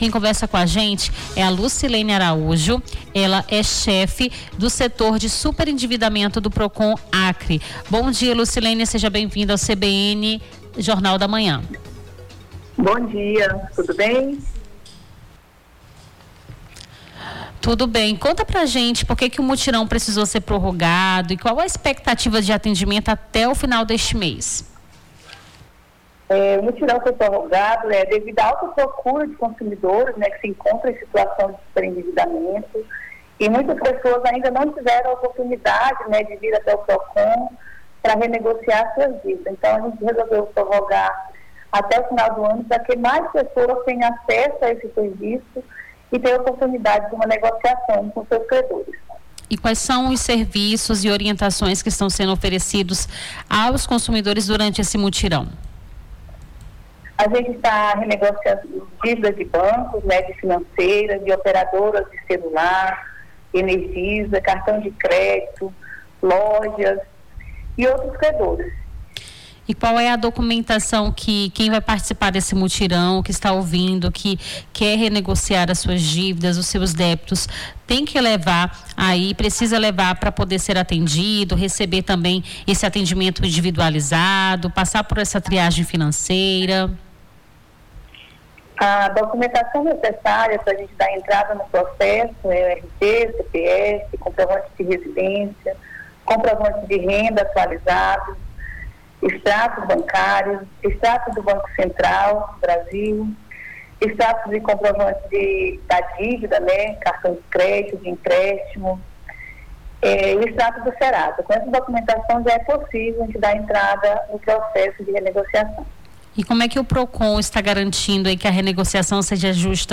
Nome do Artista - CENSURA - ENTREVISTA (MUTIRÃO PROCON) 26-08-25.mp3